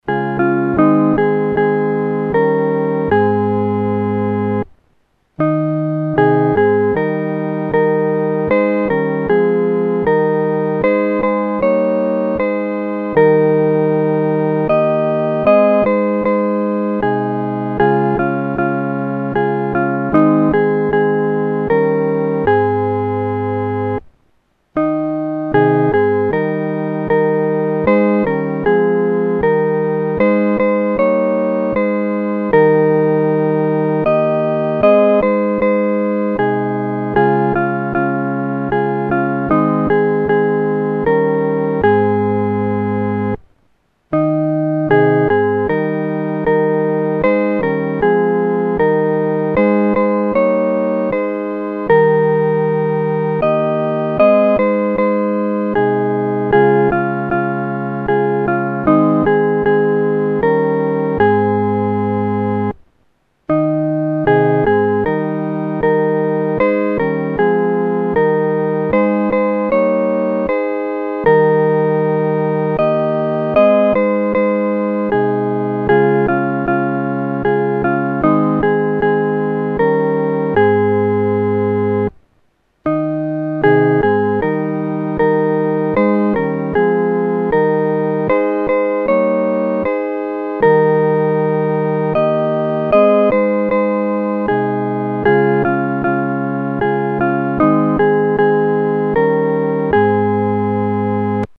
合奏（四声部）
万口欢唱-合奏（四声部）.mp3